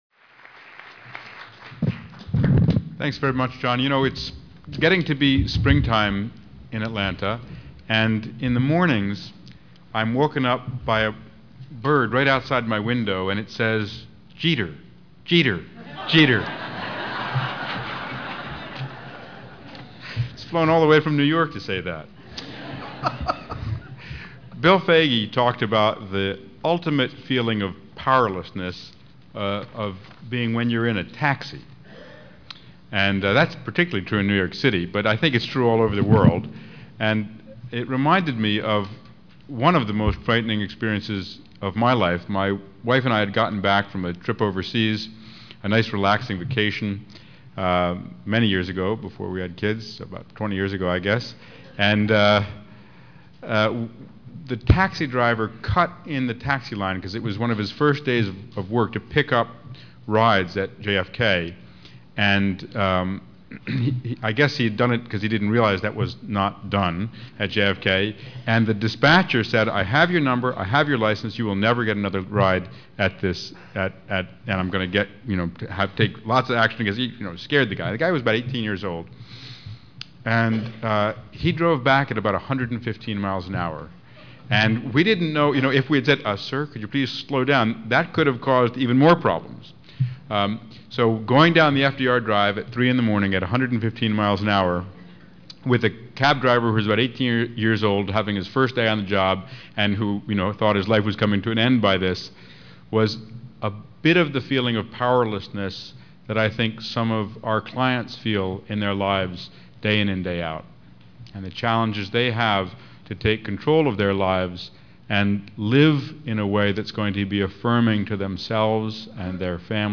Thomas Frieden, MD, MPH , Centers for Disease Control and Prevention, Atlanta, GA Audio File Recorded presentation See more of: Symposium << Previous Symposium | Next Symposium >>